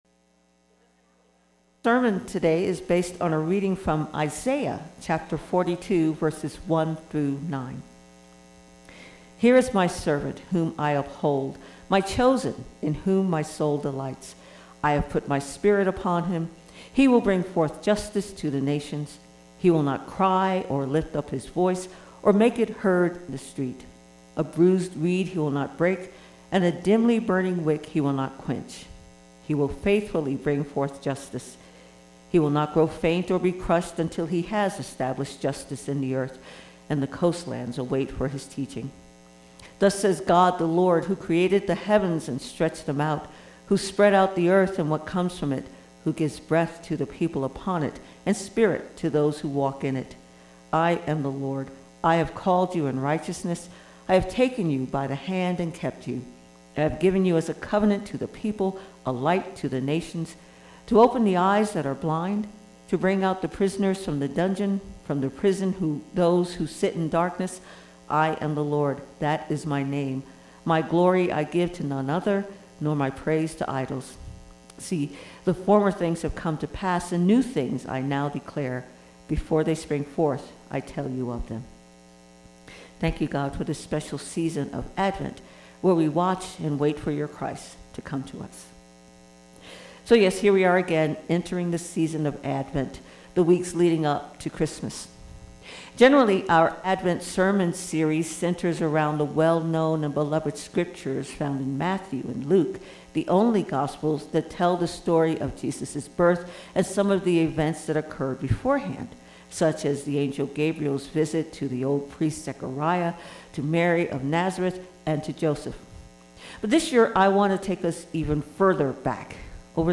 Sermon text: Isaiah 42: 1-9